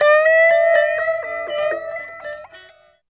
29 GUIT 4 -R.wav